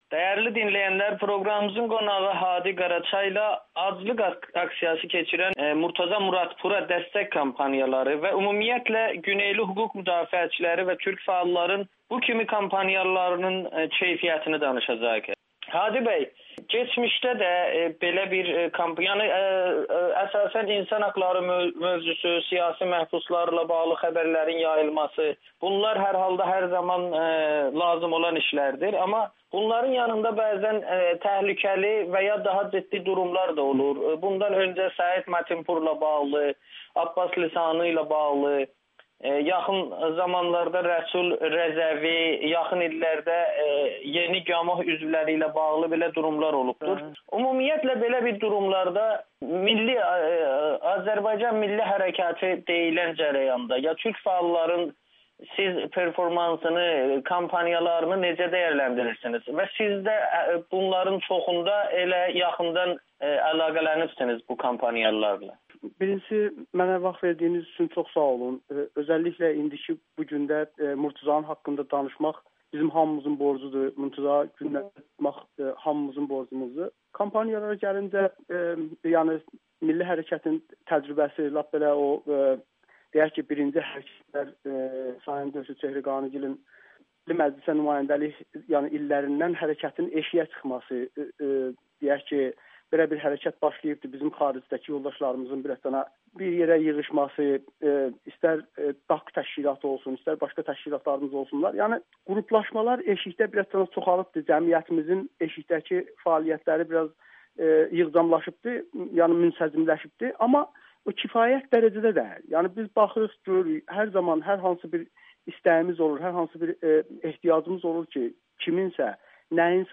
[Audio-Müsahibə]